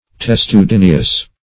Search Result for " testudineous" : The Collaborative International Dictionary of English v.0.48: Testudineous \Tes`tu*din"e*ous\, a. [L. testudineus.] Resembling the shell of a tortoise.